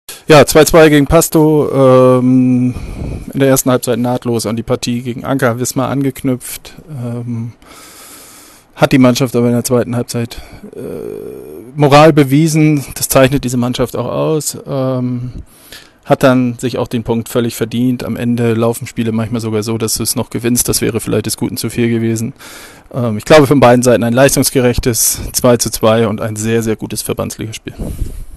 Stimmen